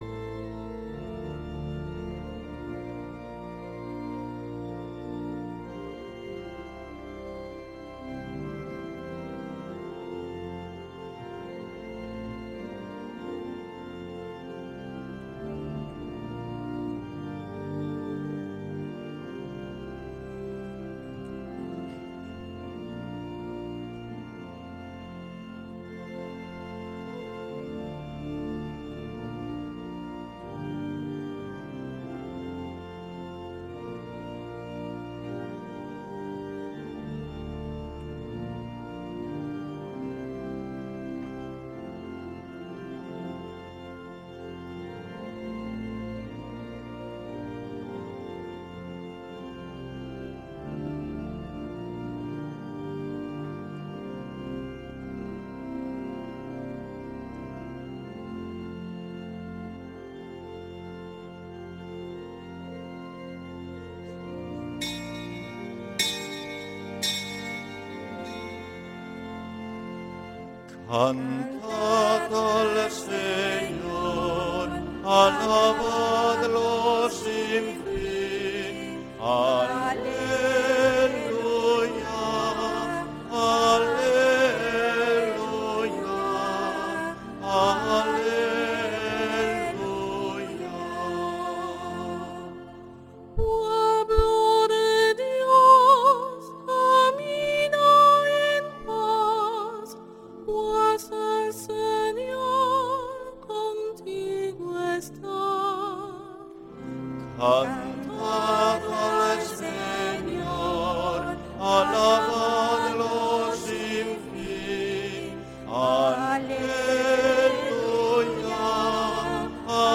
Santa Misa desde San Felicísimo en Deusto, domingo 10 de agosto de 2025